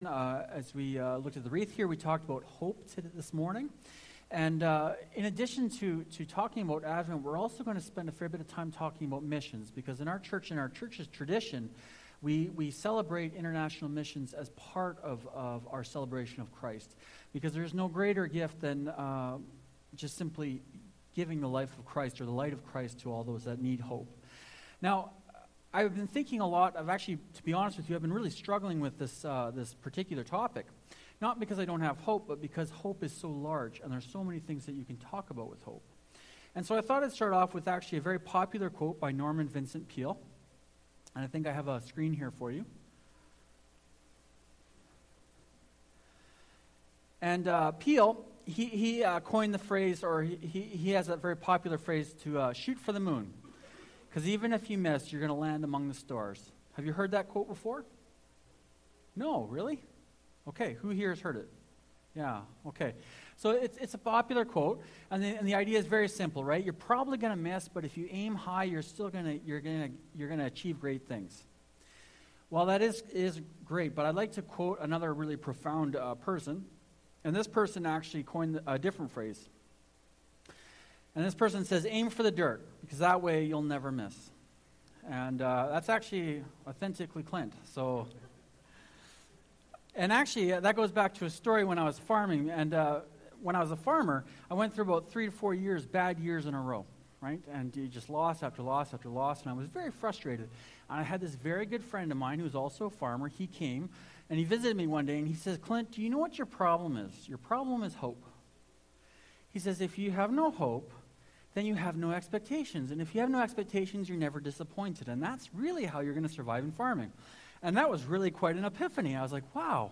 Series: 2019 Sermons, Advent